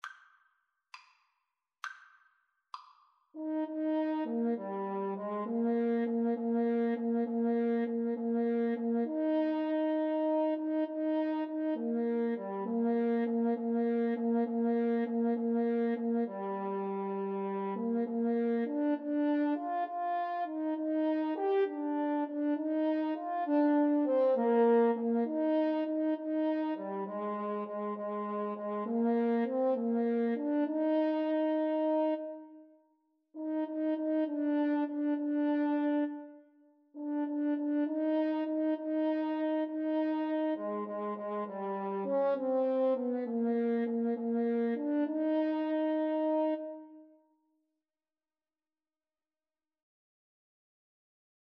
Christian
6/8 (View more 6/8 Music)